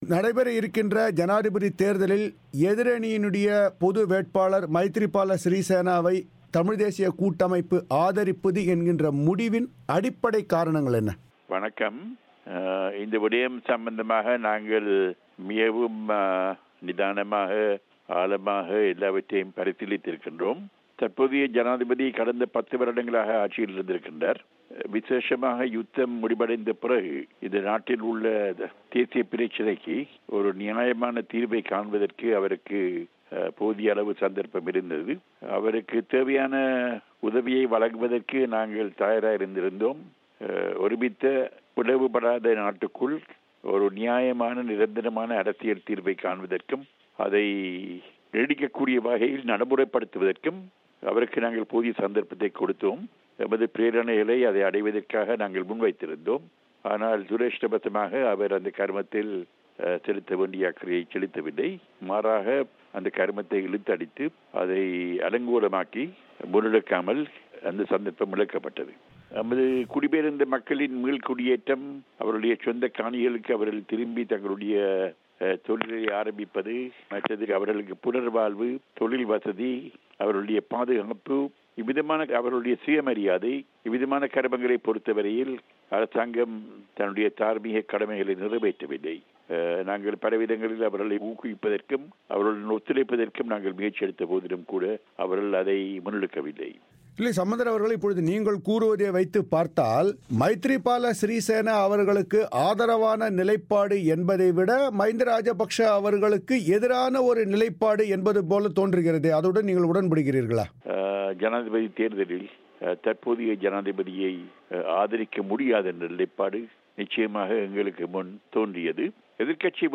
மைத்ரிபால சிறிசேனாவுக்கான ஆதரவு ஏன்? சம்பந்தர் பேட்டி